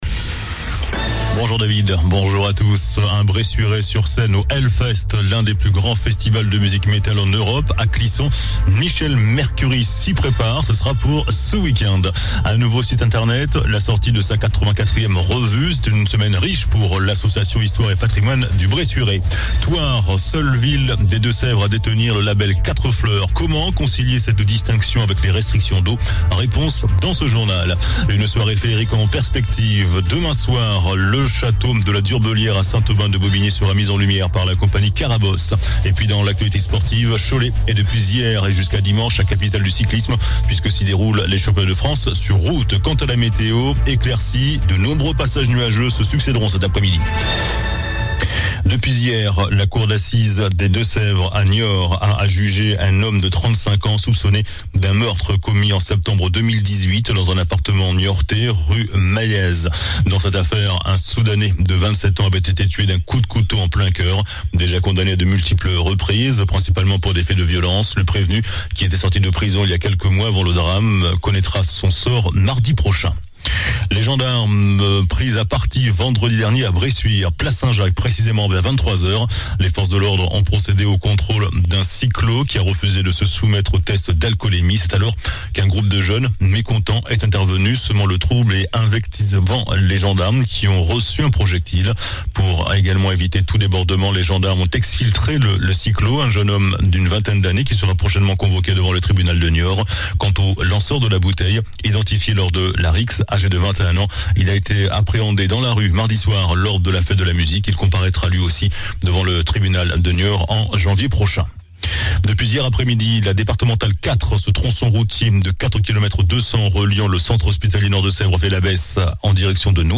JOURNAL DU VENDREDI 24 JUIN ( MIDI )